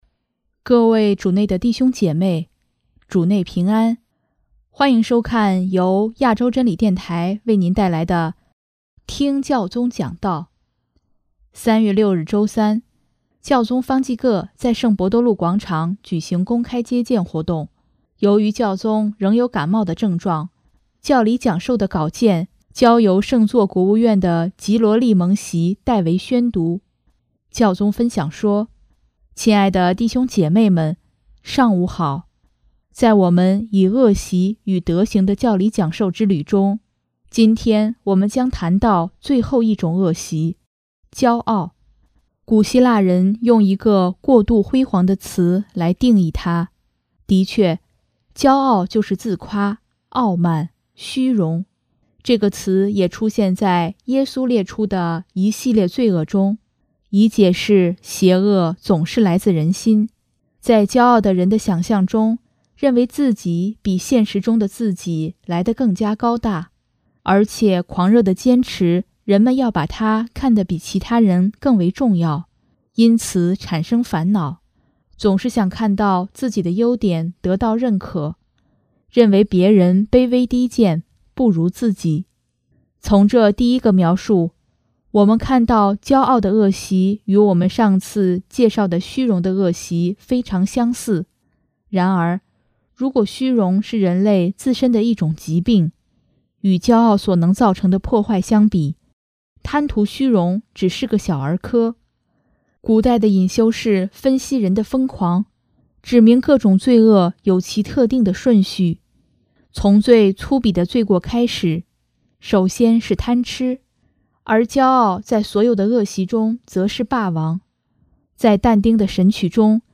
3月6日周三，教宗方济各在圣伯多禄广场举行公开接见活动